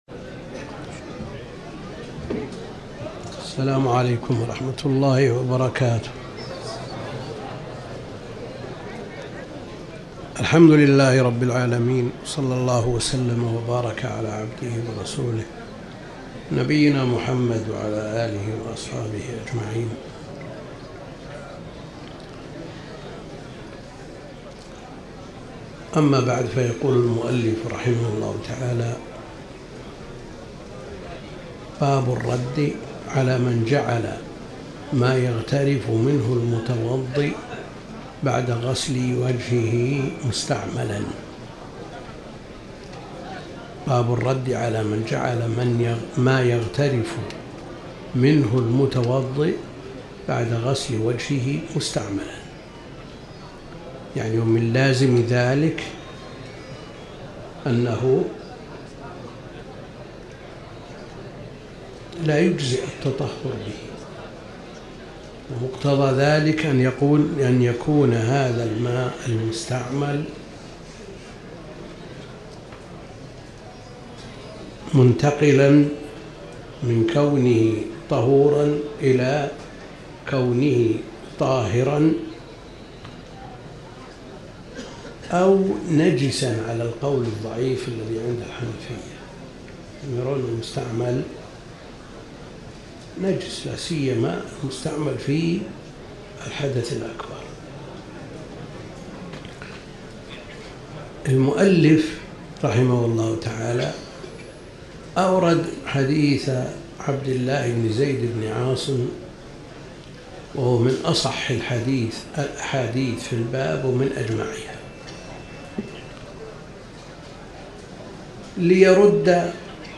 تاريخ النشر ١٠ ذو القعدة ١٤٤٠ هـ المكان: المسجد الحرام الشيخ: فضيلة الشيخ د. عبد الكريم بن عبد الله الخضير فضيلة الشيخ د. عبد الكريم بن عبد الله الخضير كتاب الطهارة